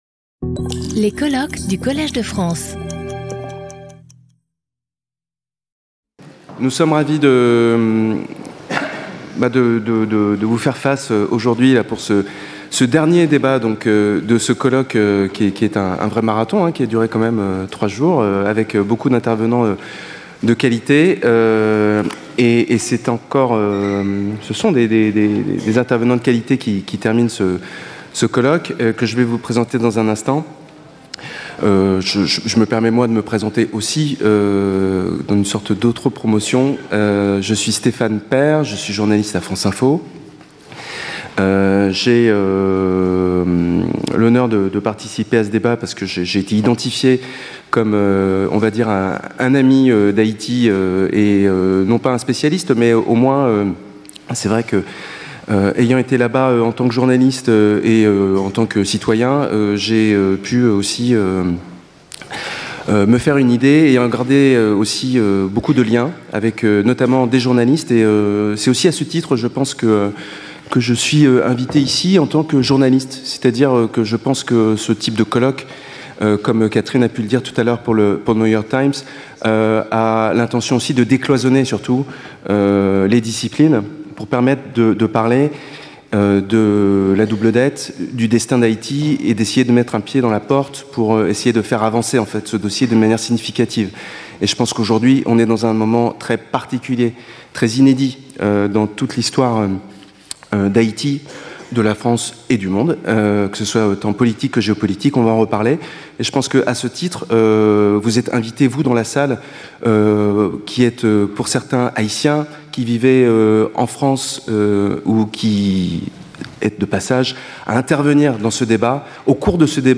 Round table : From debt to contemporary crisis | Collège de France